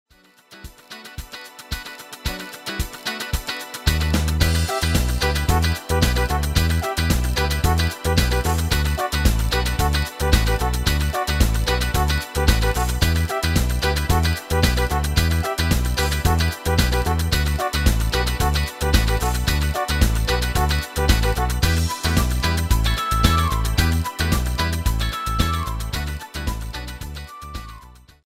Demo/Koop midifile
Genre: Evergreens & oldies
U koopt een GM-Only midi-arrangement inclusief:
- Géén vocal harmony tracks